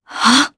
voices / heroes / jp
Isolet-Vox_Casting2_jp.wav